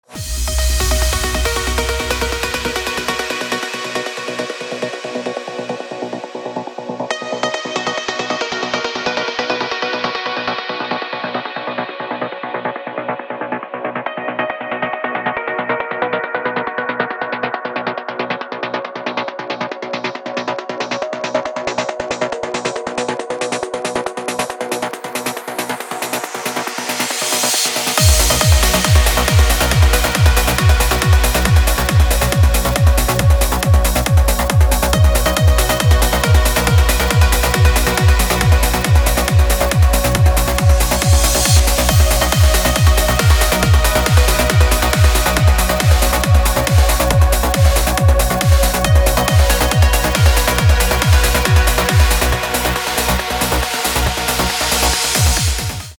громкие
dance
Electronic
электронная музыка
без слов
клавишные
club
Trance